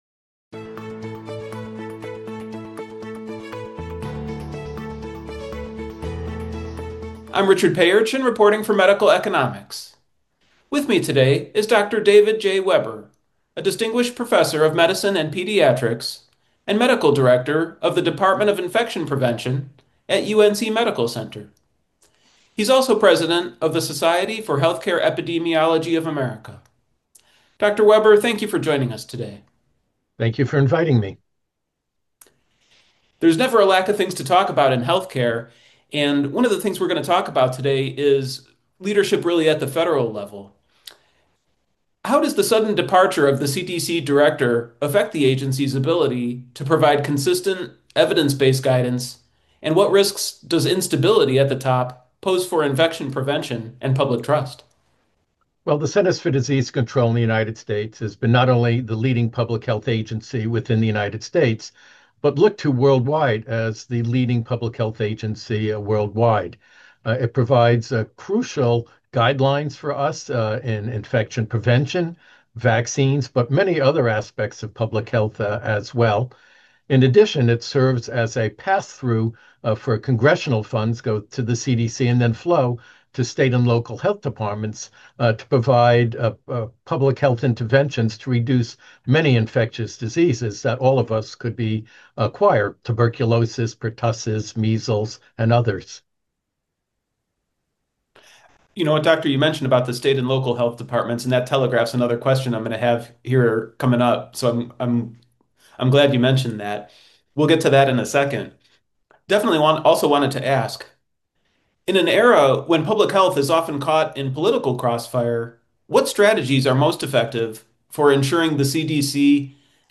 The sudden departure of the CDC director with several other top leaders has raised urgent questions about the stability of the nation’s leading public health agency and its ability to provide consistent, evidence-based guidance. In an interview